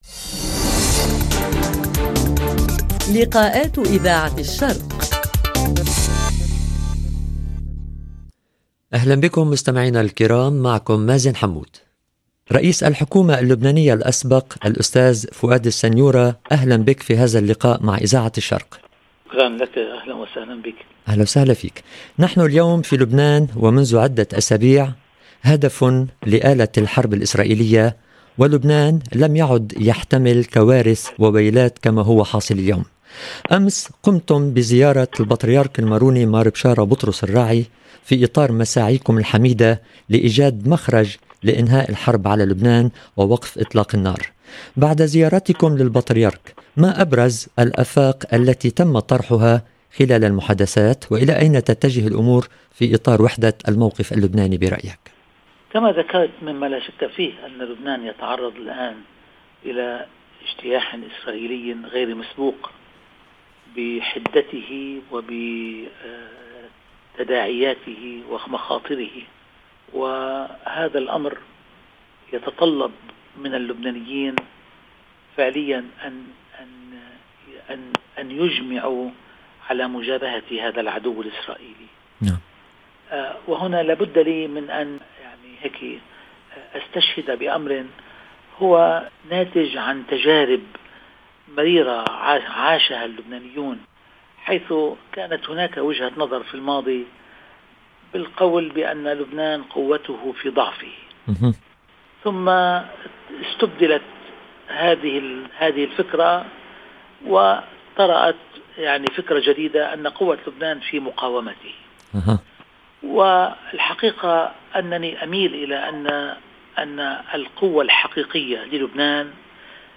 Likaat avec l’ancien premier ministre libanais Fouad Seniora